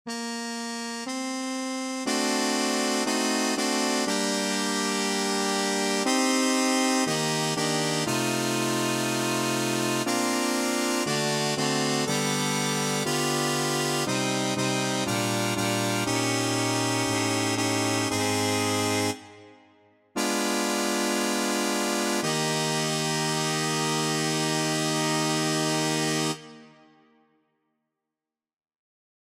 Key written in: B♭ Major
How many parts: 4
Type: Barbershop
All Parts mix: